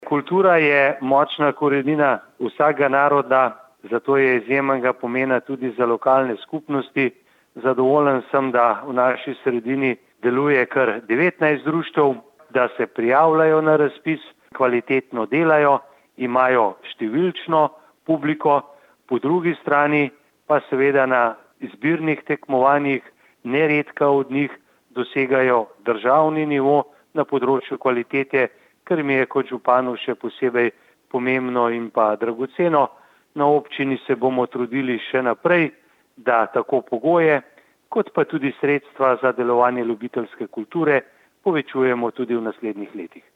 izjava_mag.borutsajoviczupanobcinetrzicokulturi.mp3 (1,0MB)